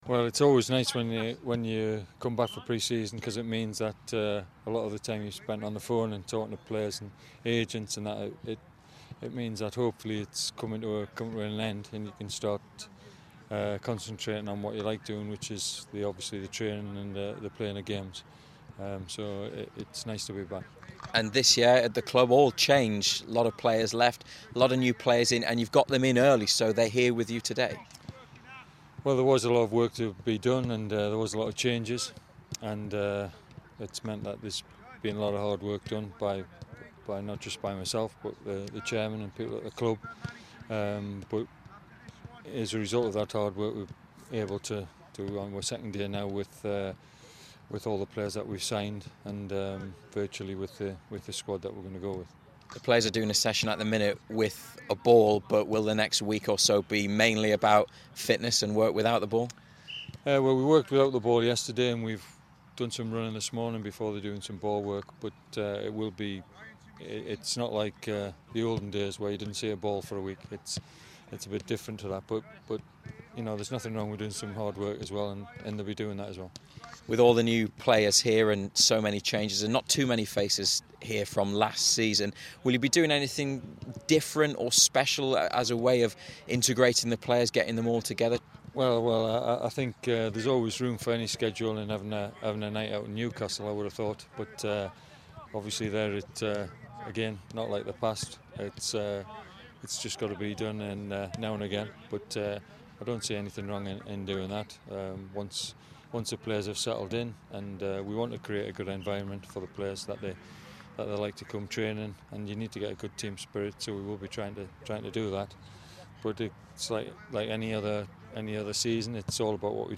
speaks to BBC Newcastle